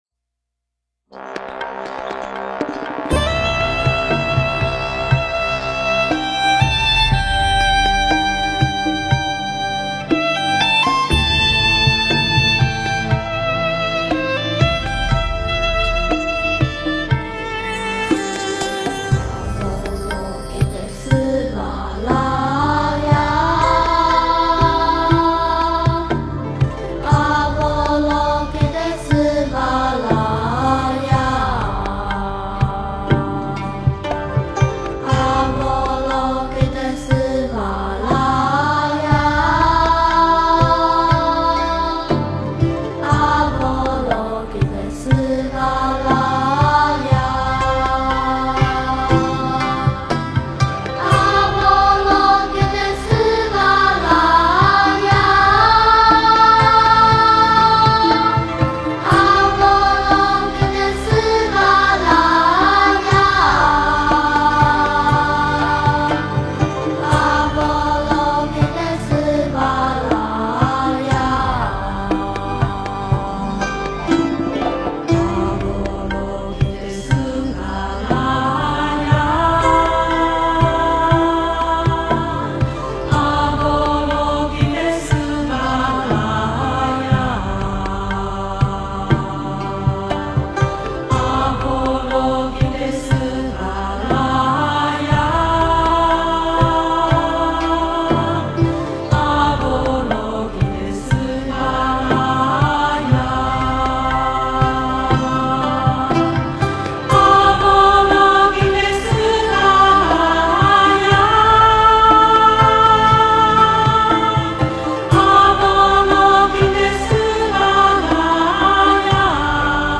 诵经 观音圣号(梵文合唱版
佛音 诵经 佛教音乐 返回列表 上一篇： 拜愿 下一篇： 观音圣号(闽南语合唱版